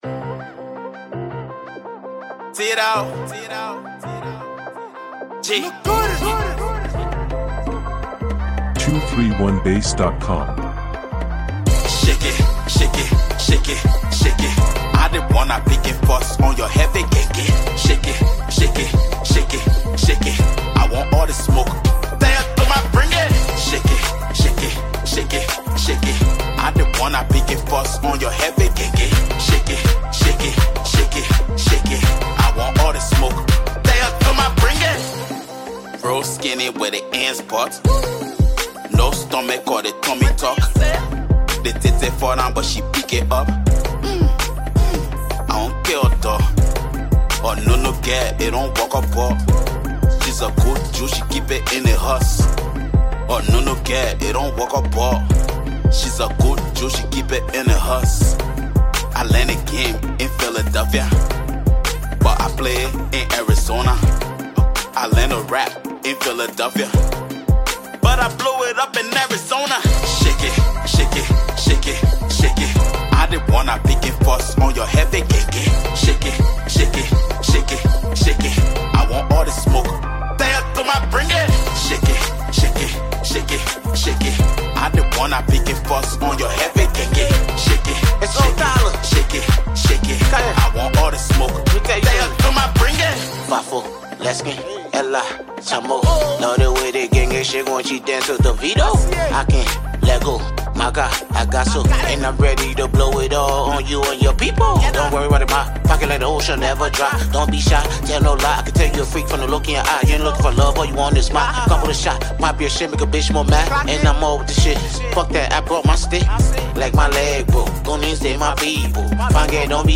upbeat anthem
catchy melodies with energetic beats